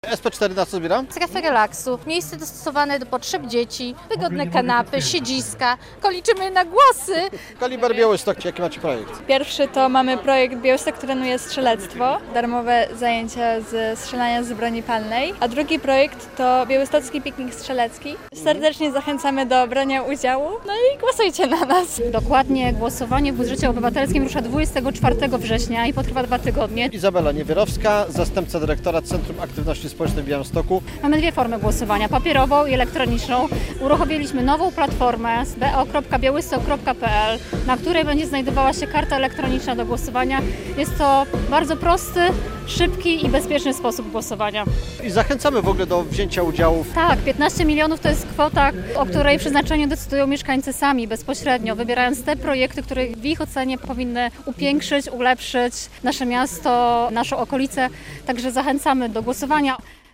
Festyn obywatelski w Białymstoku - relacja